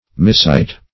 Miscite \Mis*cite"\